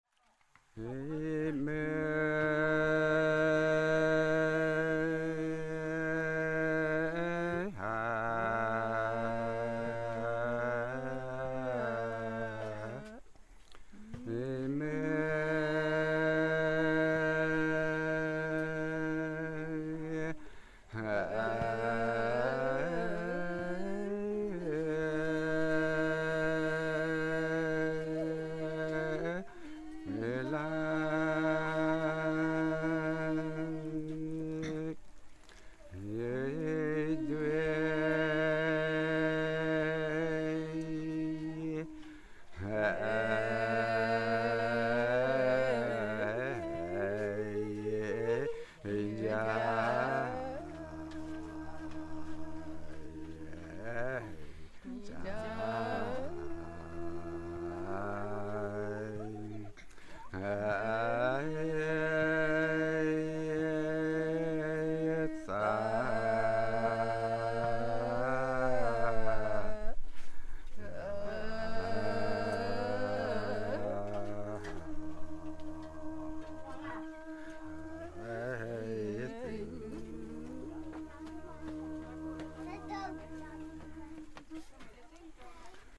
funeral song the villagers stand in a circle around the coffin before carrying it into the forest;  a male singer leads and two women join in 1.3MB